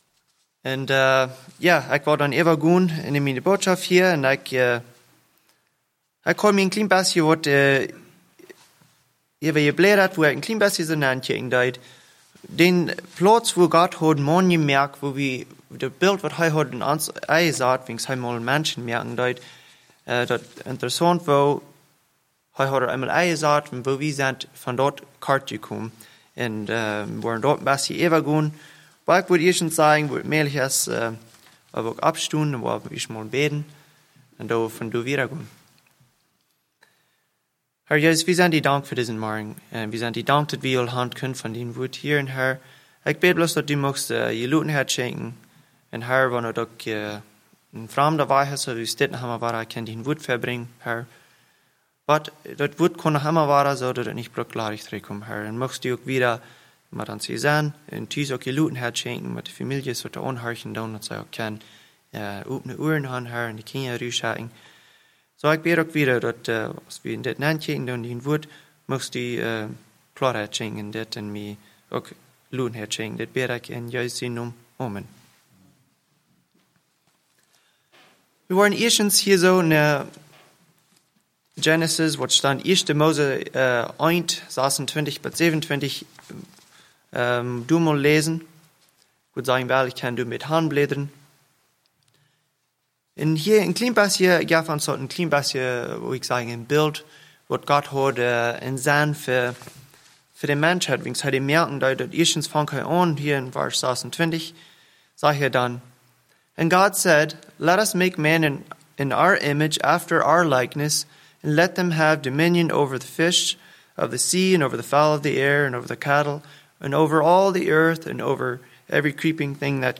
message brought on May 30, 2021